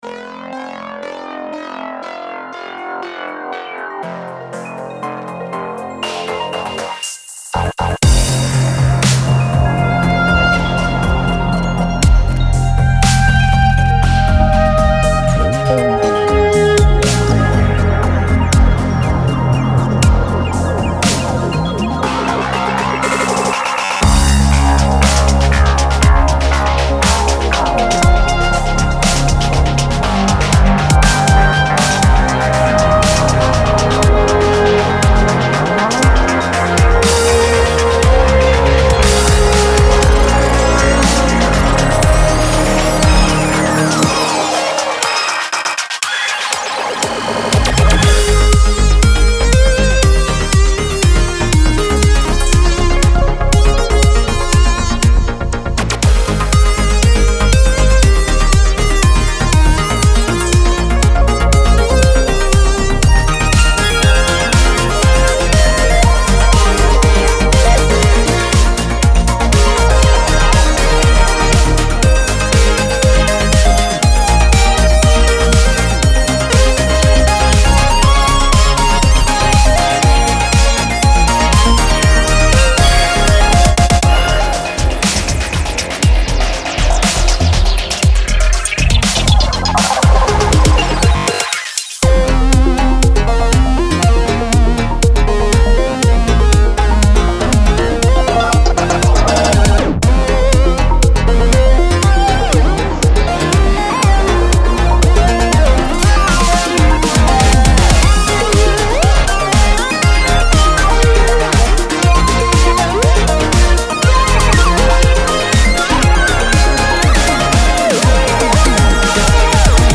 Fantastically reaching back to the 80s.